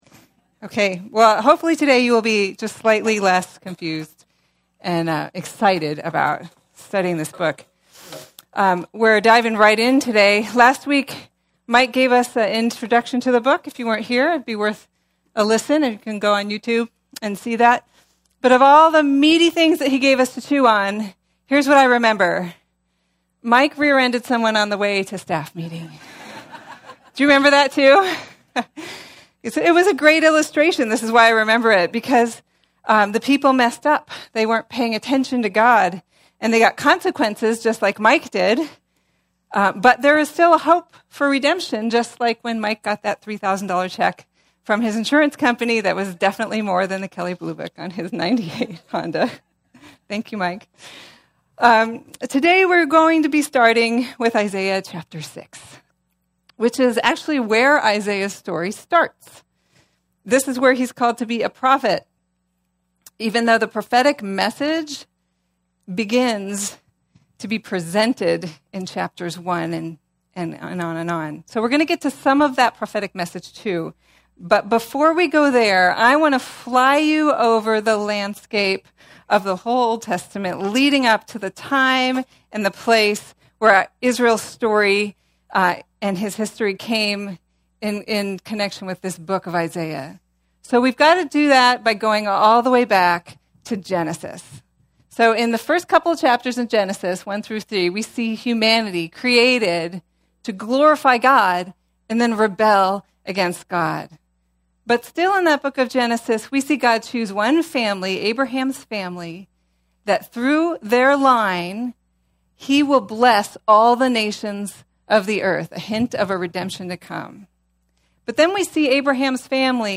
Isaiah Passage: Isaiah 6:1-13 Service Type: Sunday Topics